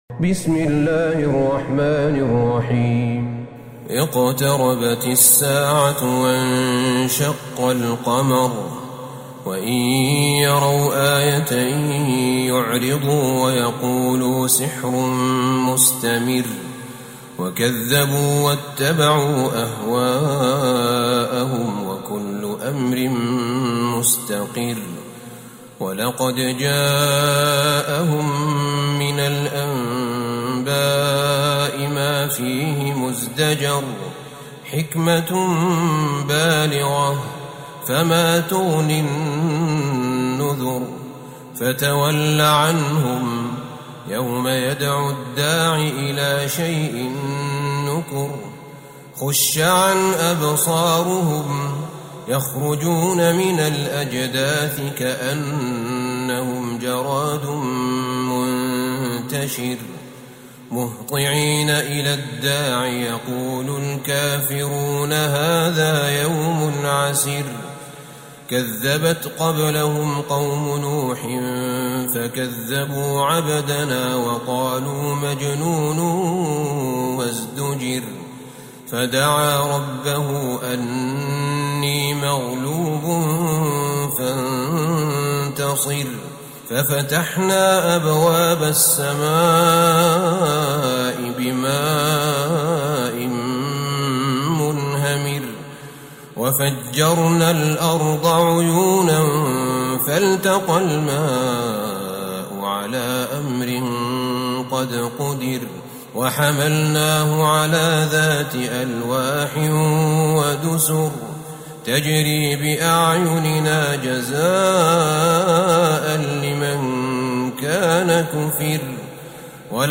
سورة القمر Surat Al-Qamar > مصحف الشيخ أحمد بن طالب بن حميد من الحرم النبوي > المصحف - تلاوات الحرمين